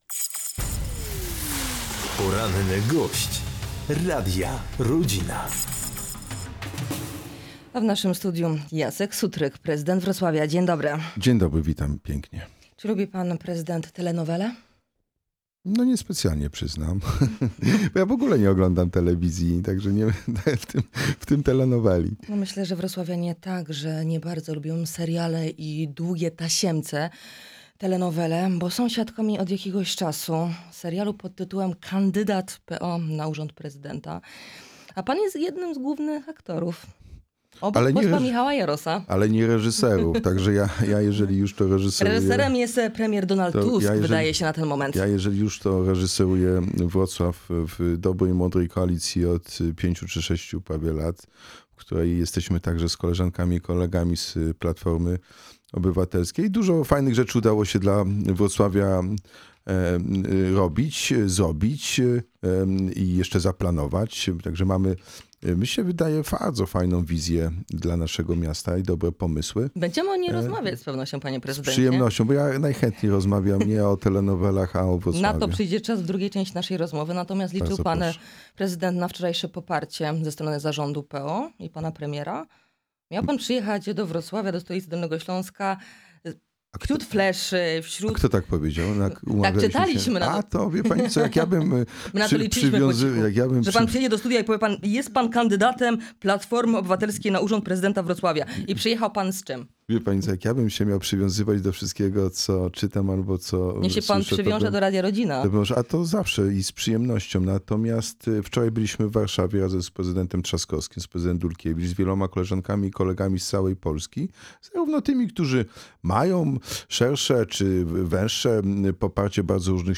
Michale, jeśli mnie słyszysz jestem gotowy na współpracę – tymi słowami zwrócił się na antenie prezydent Wrocławia Jacek Sutryk do szefa dolnośląskich struktur.